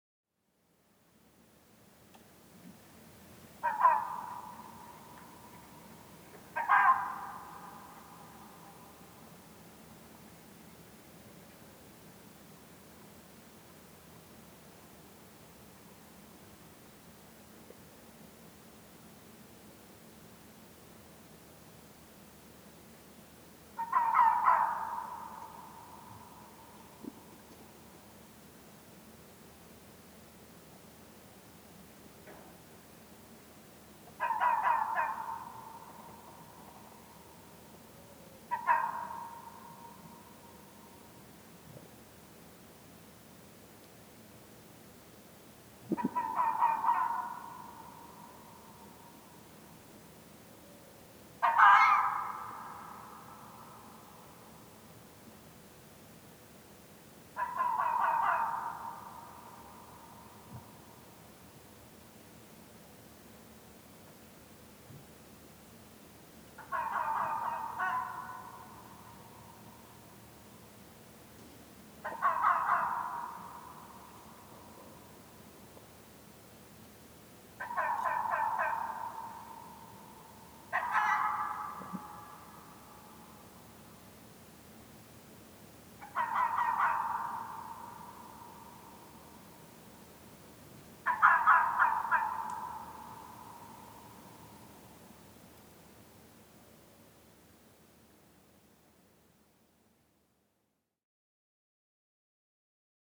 Nisäkkäiden ääniä, 1. osa: Kettu
Tässä 9-osaisessa sarjassa tutustumme nisäkkäiden ääniin. Ensimmäisenä vuorossa on kettu ja sen haukahdukset.